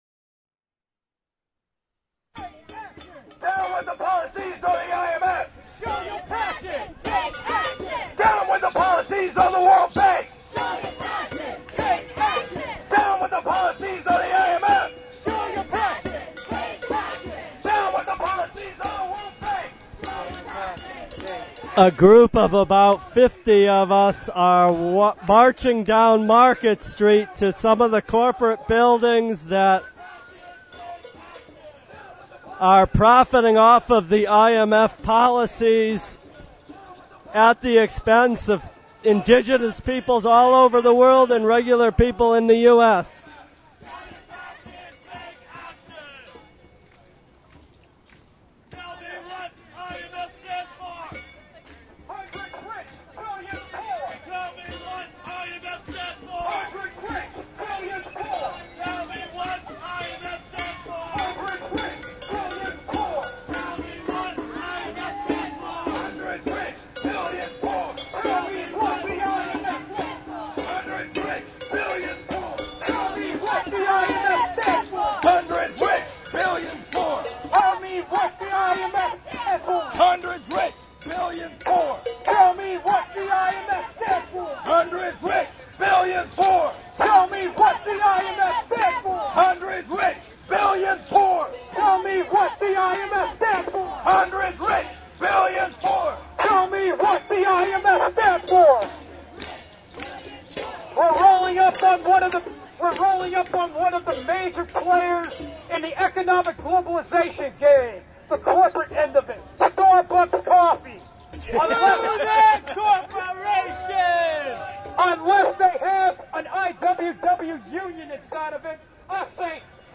Protestors stopped in front of numerous transnational businesses, denouncing their business practices. Good political theatre with punk-oriented in-your-face rhetoric led by one of the Globalize This! activists.